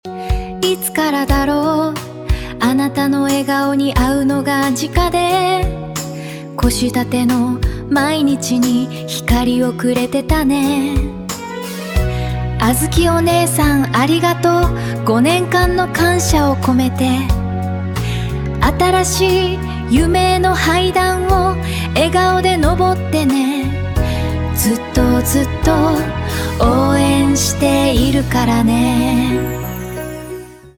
テキストで指示を出すだけで、歌詞の書き下ろしからメロディ、歌声の生成までをわずか数十秒で行なってくれます。
スタイル R&B ロマンス
ただ、日本語としての発音に違和感があるところや、何と言っているのか聞き取れないところもありました。